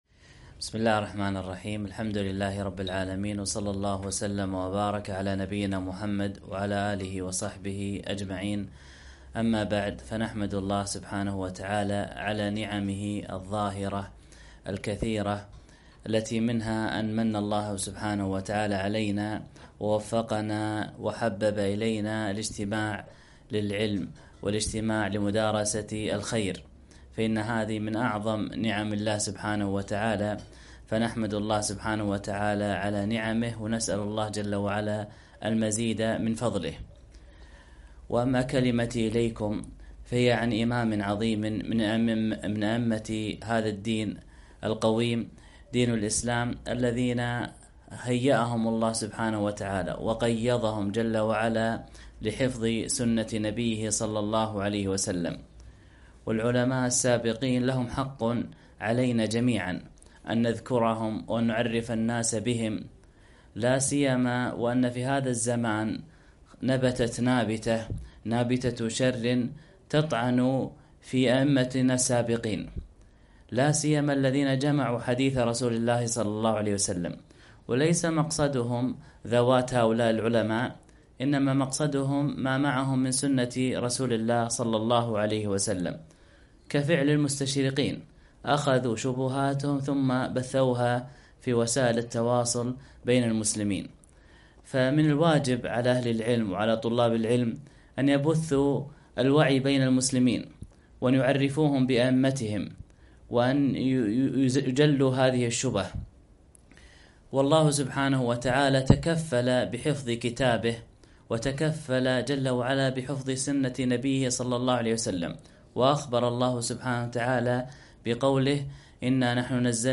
محاضرة - ومضات من سيرة الإمام البخاري